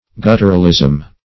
Search Result for " gutturalism" : The Collaborative International Dictionary of English v.0.48: Gutturalism \Gut"tur*al*ism\, n. The quality of being guttural; as, the gutturalism of A [in the 16th cent.].
gutturalism.mp3